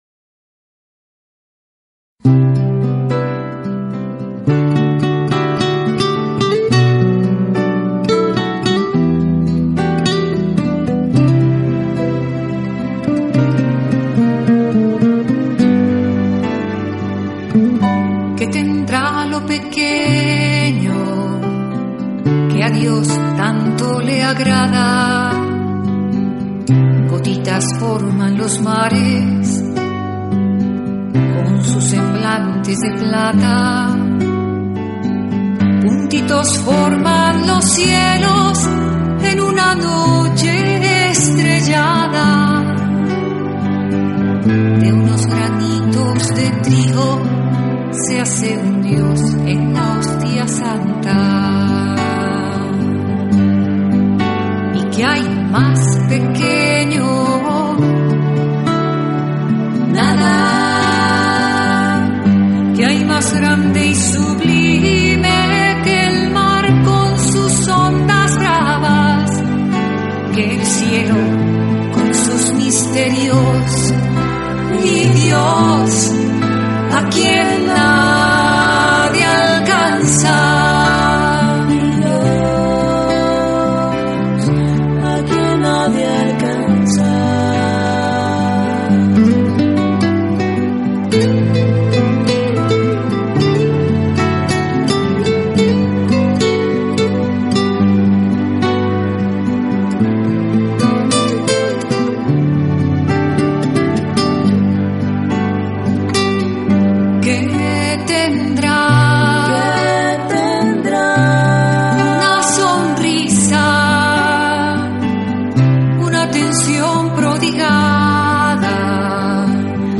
Una letra y música llena de sensibilidad, ternura, espiritualidad y realismo.